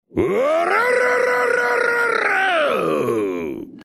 Download “blackbeard laugh” blackbeard-laugh.mp3 – Downloaded 5877 times – 211.57 KB